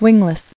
[wing·less]